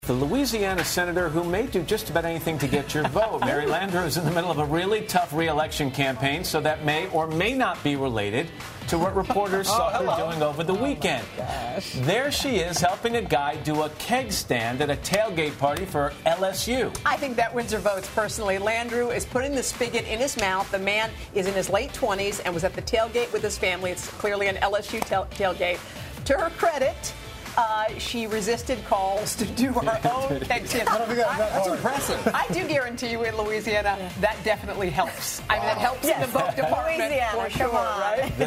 The hosts on NBC's Today spent over a minute of air time on Monday applauding Louisiana Democratic Senator Mary Landrieu for helping a fan tailgating at an LSU football game do a keg stand.
Here is a full transcript of the September 22 exchange on Today: